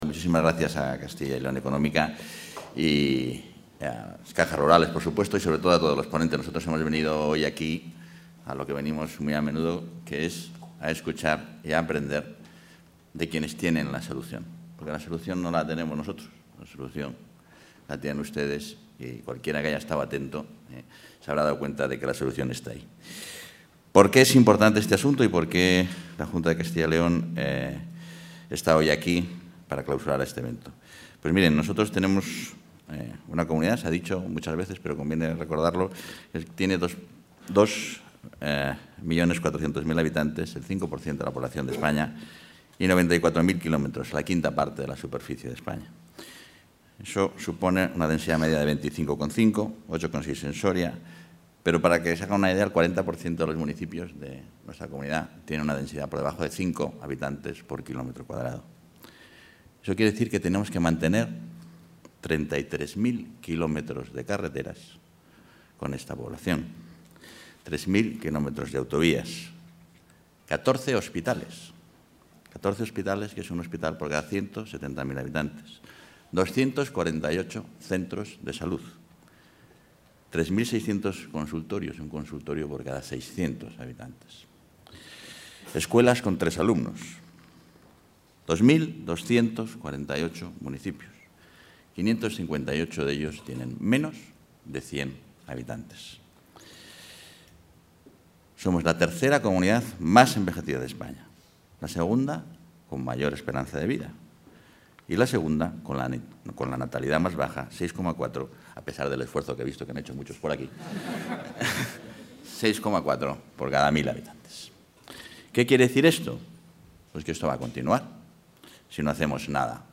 Audio vicepresidente.